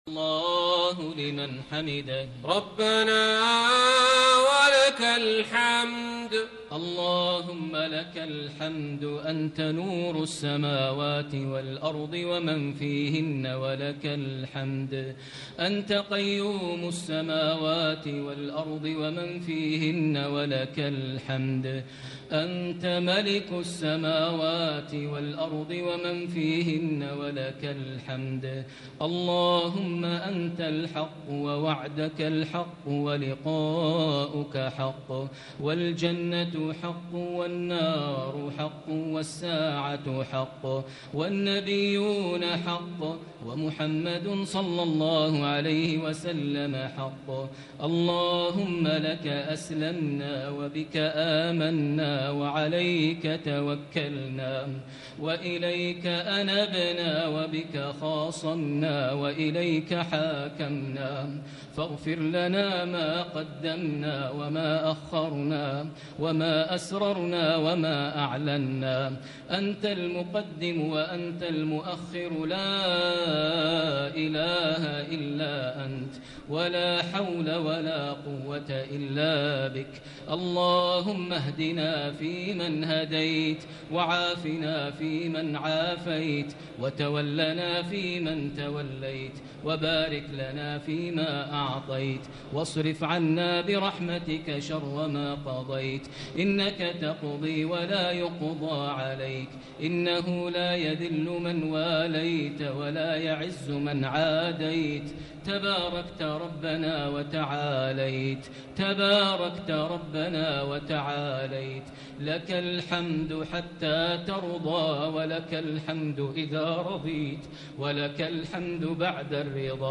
الدعاء
المكان: المسجد النبوي الشيخ: فضيلة الشيخ ماهر المعيقلي فضيلة الشيخ ماهر المعيقلي الدعاء